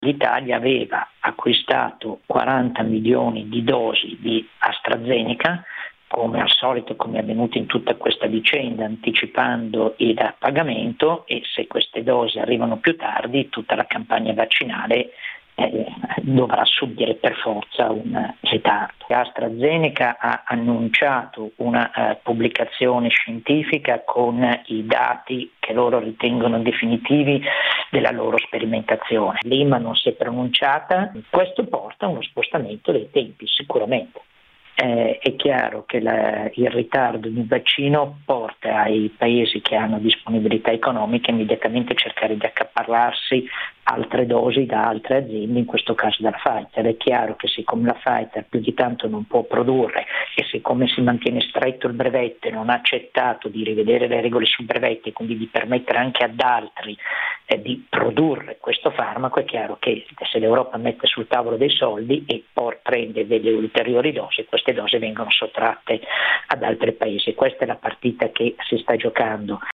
medico e nostro collaboratore